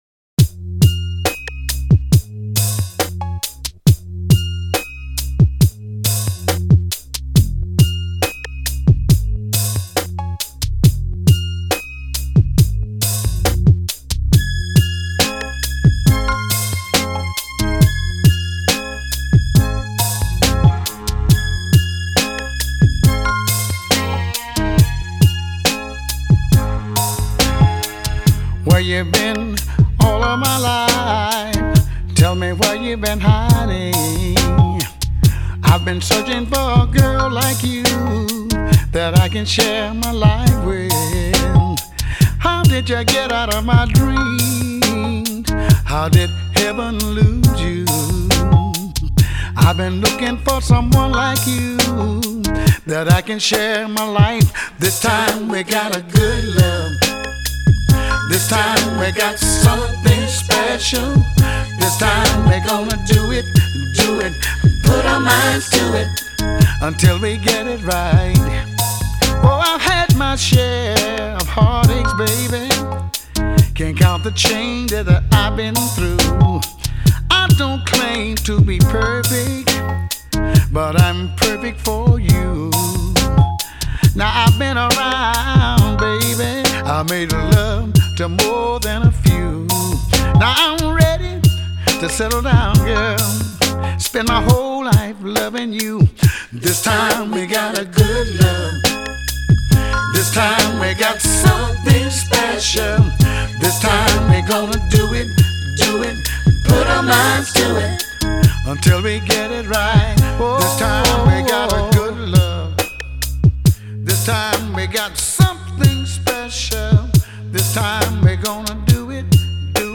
RnB
Some good ole' Blues